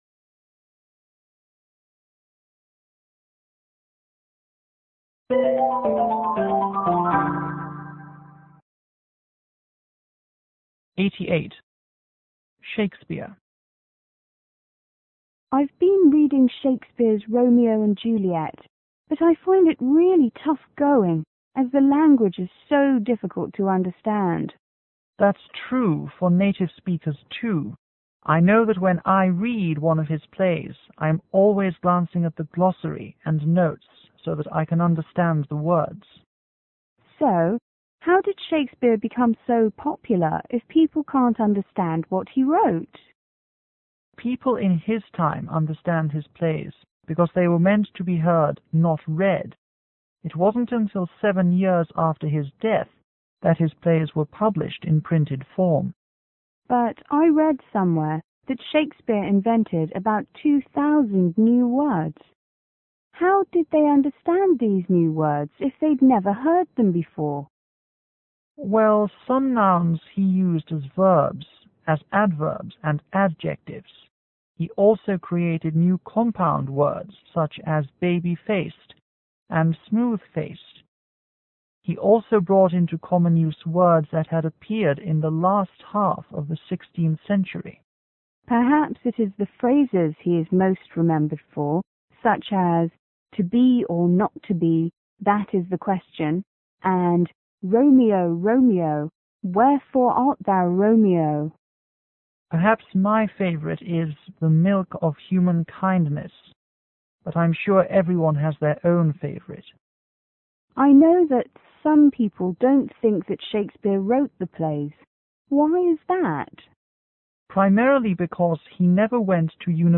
C: Chinese student     E: English teacher